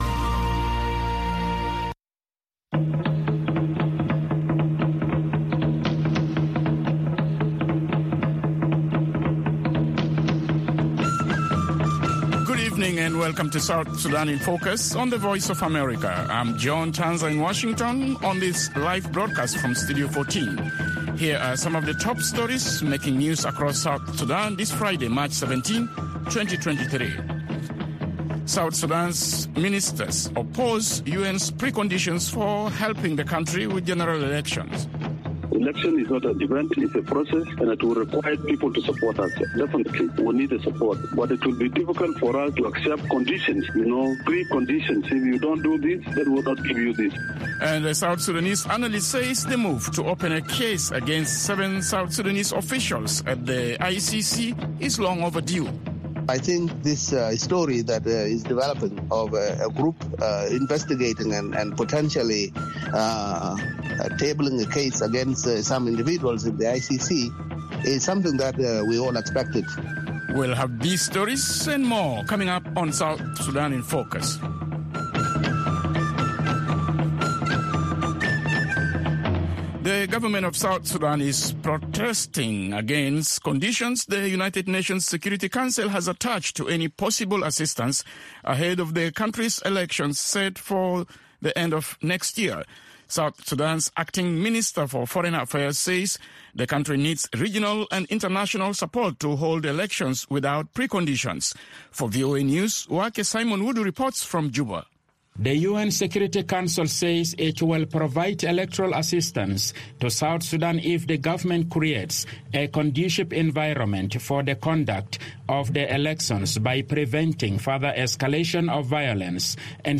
and a network of reporters around South Sudan and in Washington.